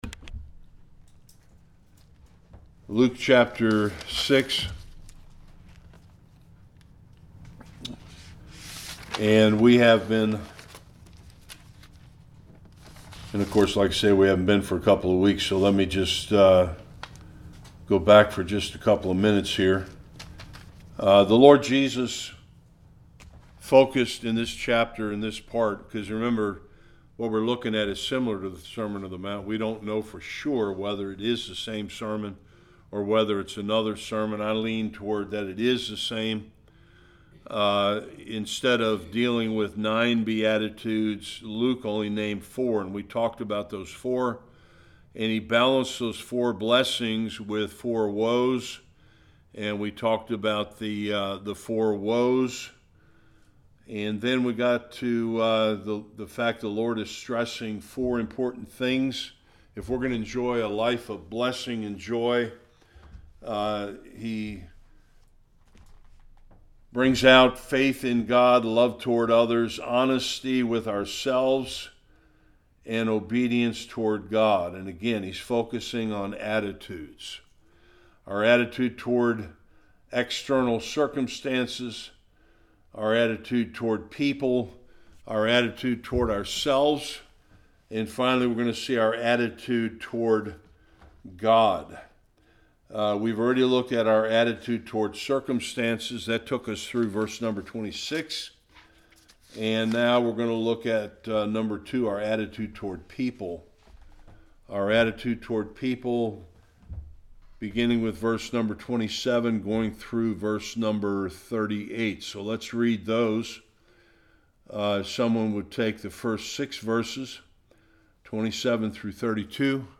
27-45 Service Type: Bible Study A continuation of the proper Christian attitudes to help produce victory and joy in our lives.